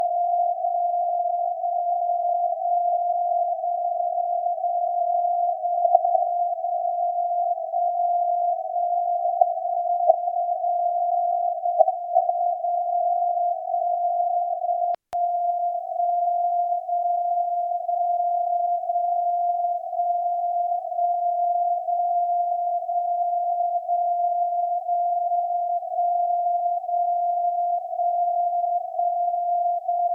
Für den Vergleich habe ich Audioaufnahmen der beiden Empfänger gemacht.
Sekunde 0-15 >> SDRplay RSPduo
Sekunde 15-30 >> Winradio G33DDC Excalibur Pro
CW
Timesignal Anthorn GBR